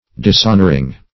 Dishonor \Dis*hon"or\ (d[i^]s*[o^]n"[~e]r or